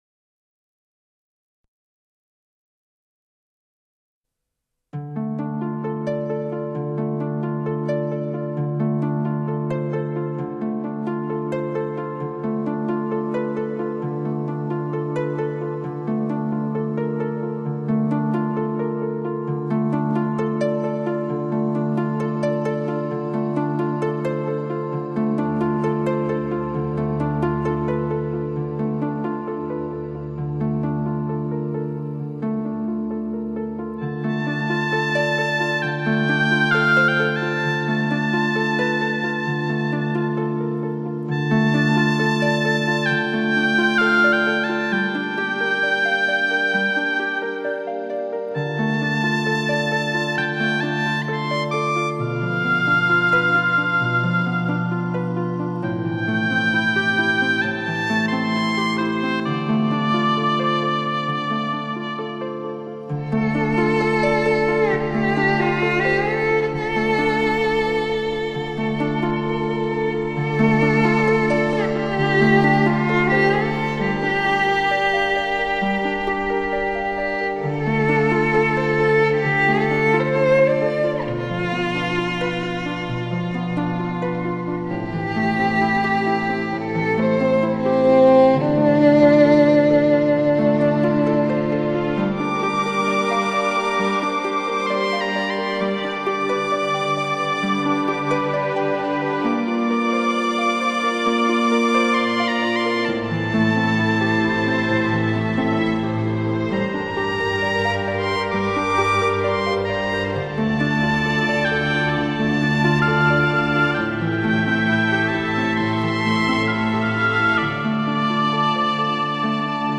现代与古老文化的雅致结合 触摸一个民族的纤细感情,品味最深沉的爱与忧伤 让我们感受蓝天白云下极目无边的辽阔 看阳光下飞驰的骏马、信步的羊群 聆听蒙古人深情悠长的歌声 品味如歌往事、浪漫情怀